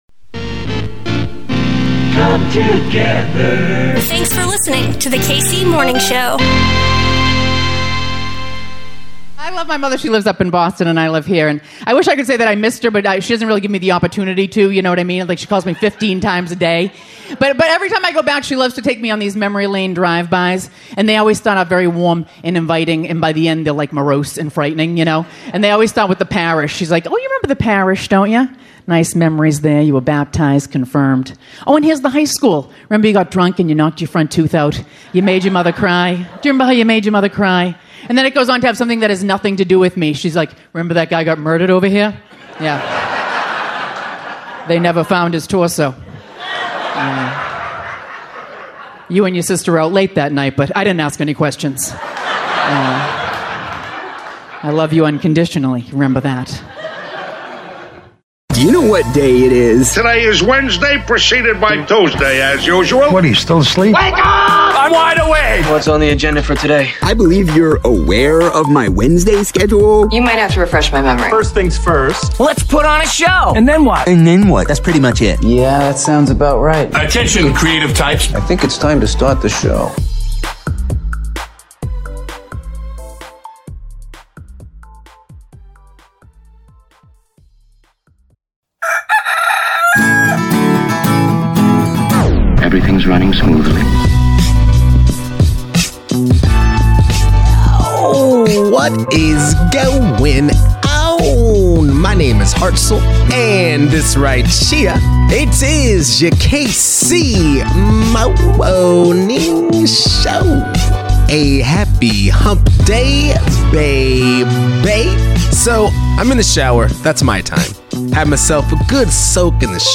From YOUR KC Morning Show!ON the show today, KC's very own, Blue False Indigo LIVE from The Rino in NKC!It's A Good Day To Be A Kansas Citian.